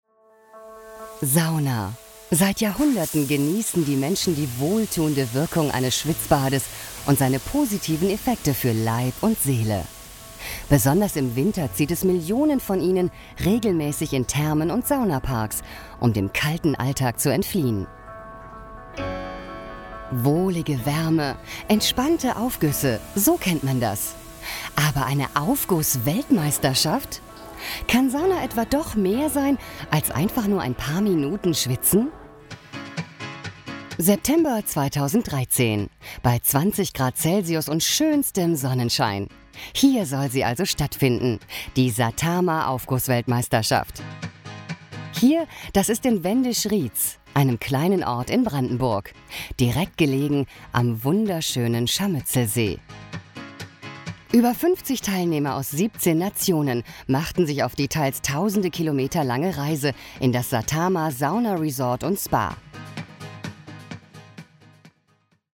seit über 20 Jahren Profi-Sprecherin, bekannte Stimme aus Funk und TV, Werbung und Service-Telefonie, volle, warme, weibliche Stimme, sehr wandelbar von werblich über seriös/ernst zu informativ und freundlich
Kein Dialekt
Sprechprobe: Industrie (Muttersprache):